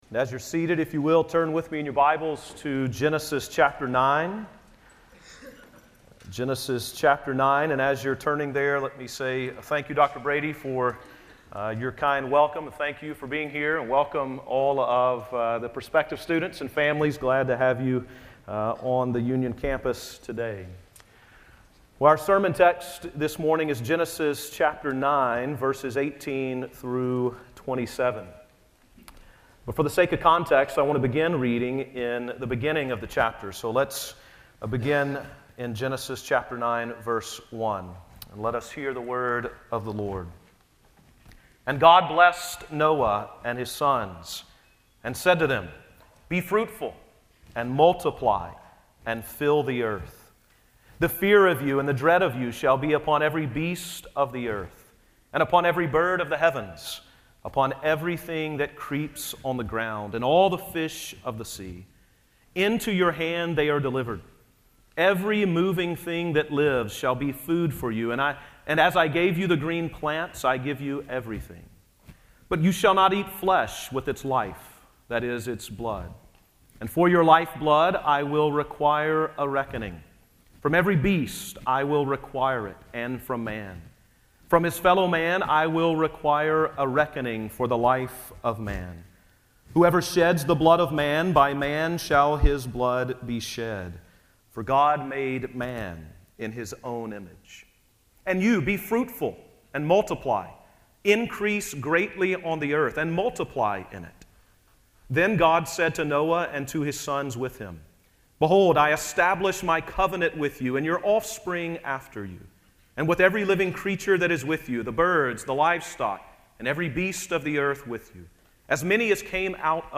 Chapels